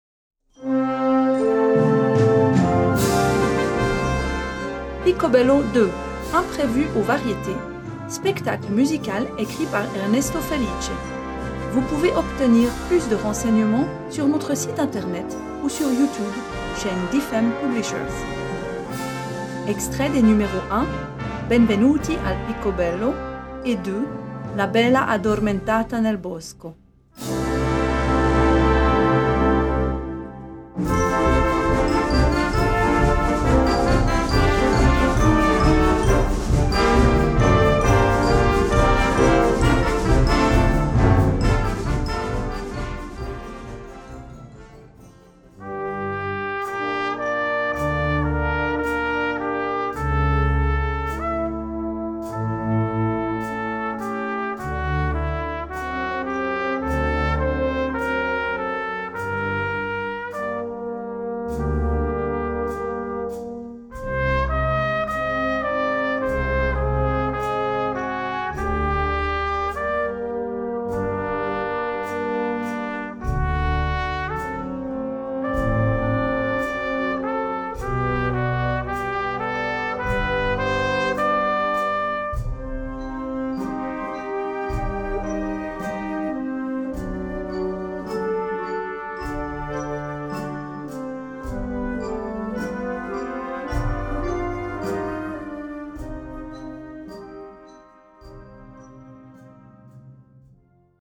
Wind Band (harmonie)
Young Band/Jugend Band/Musique de jeunes
Easy Listening / Unterhaltung / Variété